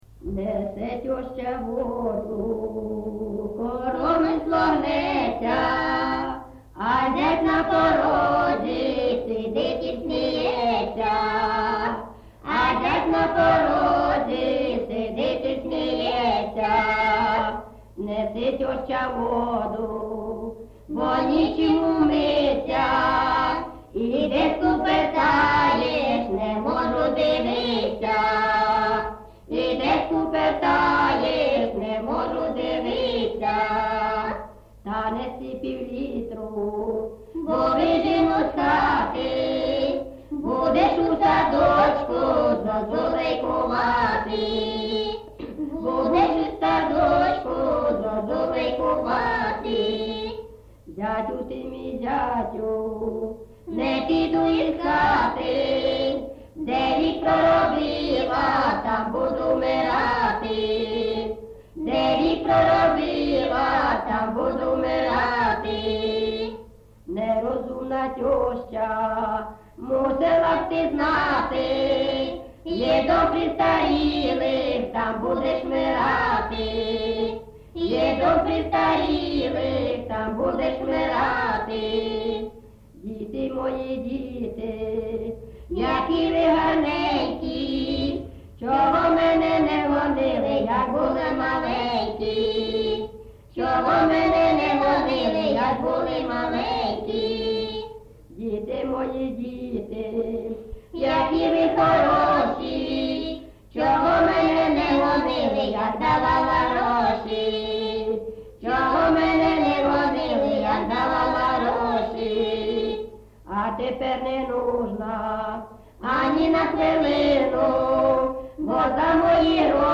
ЖанрСучасні пісні та новотвори
Місце записус. Золотарівка, Сіверськодонецький район, Луганська обл., Україна, Слобожанщина